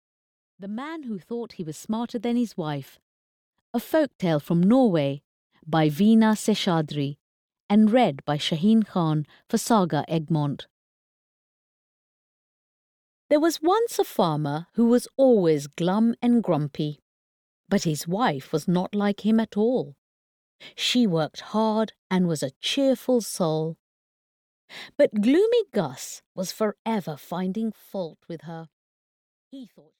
The Man who Thought he was Smarter than his Wife (EN) audiokniha
Ukázka z knihy